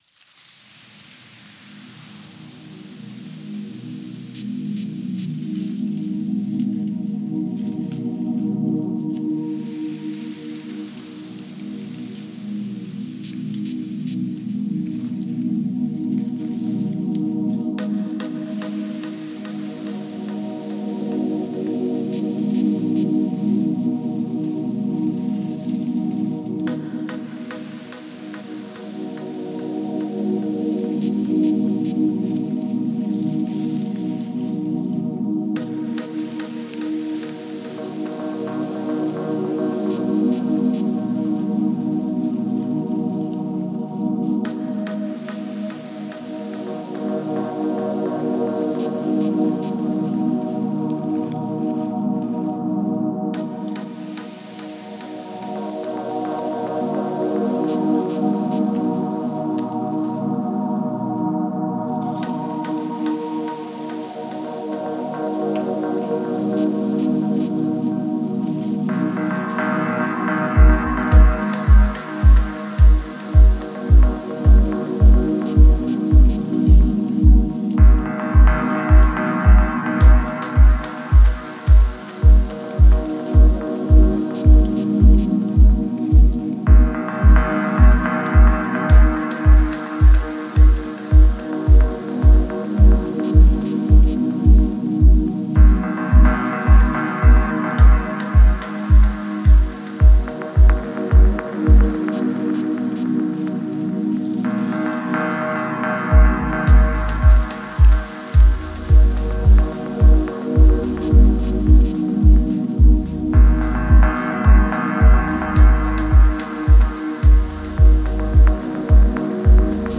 Genre: Dub Techno/Techno.